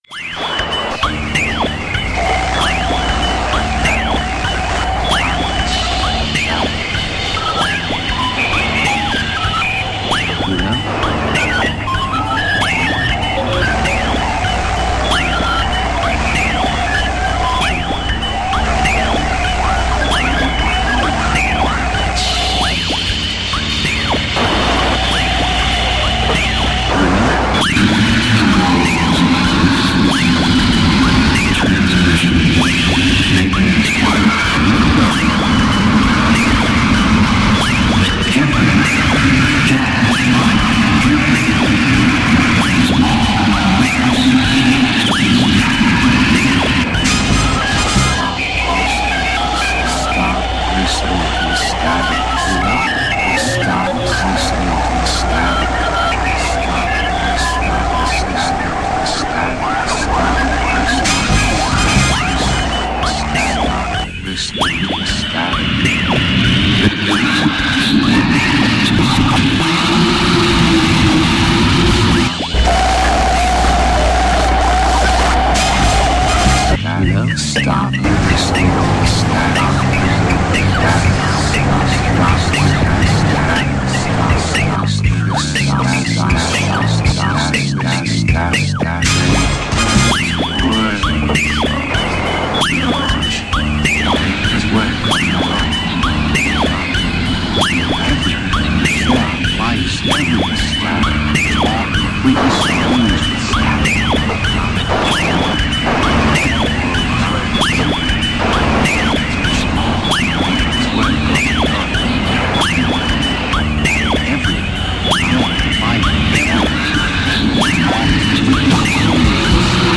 (28:00) Mash-up of "Twilight Zone" episode "Static," with 31 Down's radio play "Dead Dial Tone," and other static and dial sounds.